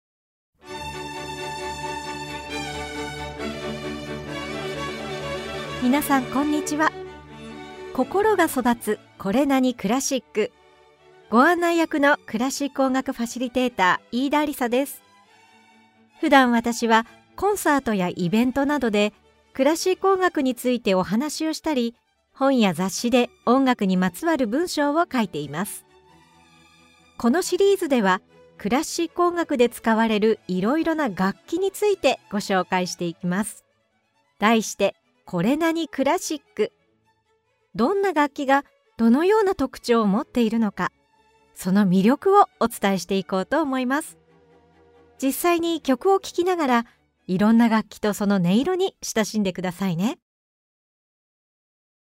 パイプオルガンは、様々な音色を出せ、たった1人でオーケストラのように演奏することができることから「楽器の王様」と呼ばれることもある楽器。オルガンといえばこの曲！という有名なバッハの作品、学校のチャイムのメロディが登場する「ウェストミンスターの鐘」などを紹介しながら、パイプオルガンのさまざまな音色とその魅力を紹介します！
[オーディオブック]